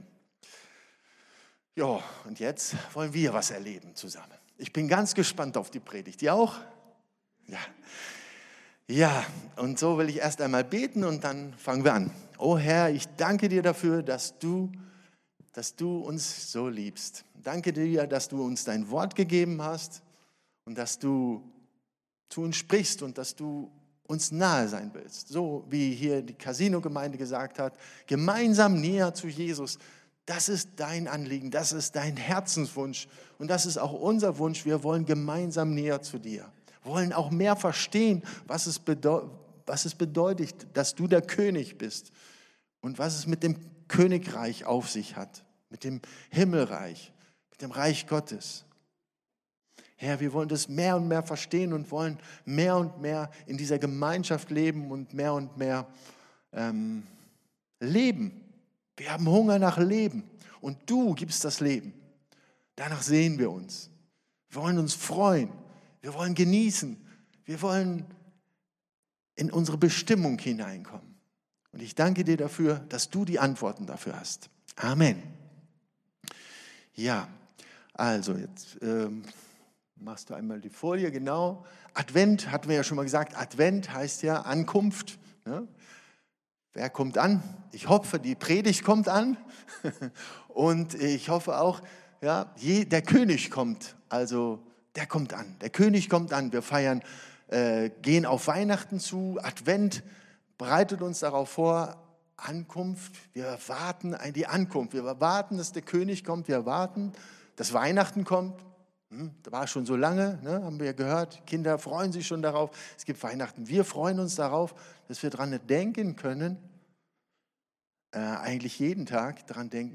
Eine Predigt